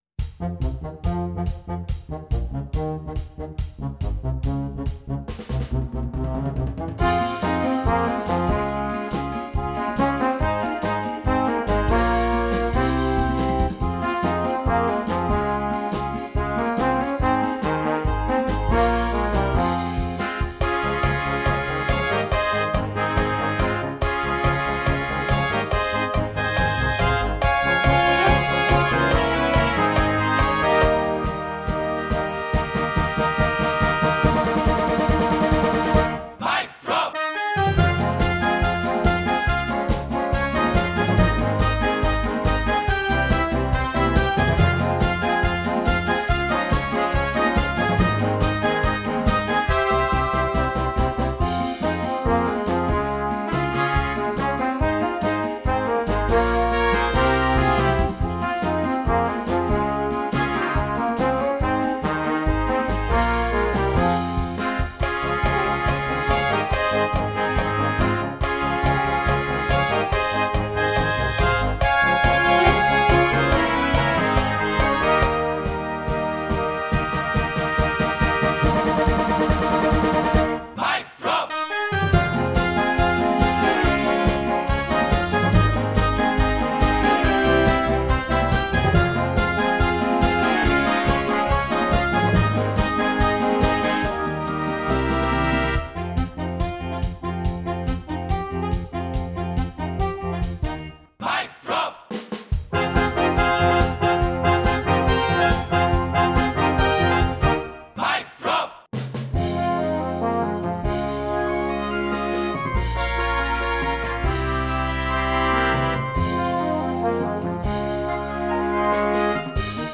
Gattung: Moderner Einzeltitel für Blasorchester
Besetzung: Blasorchester